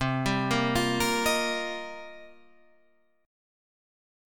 Cm11 chord